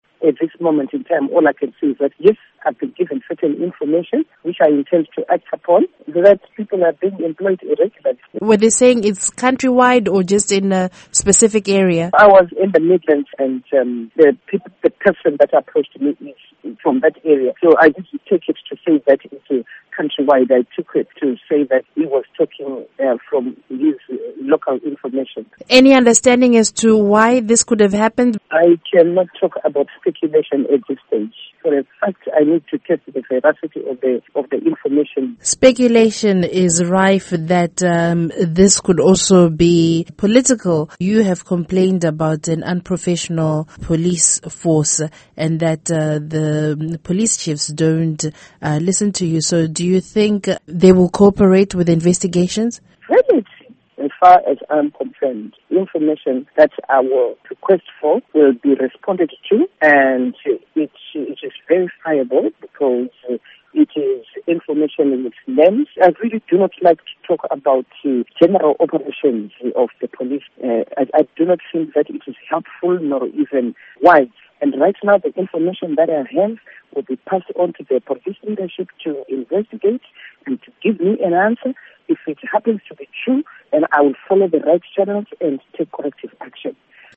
Interview with Theresa Makone